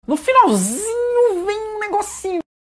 davy jones no finalzinho vem um negocinho Meme Sound Effect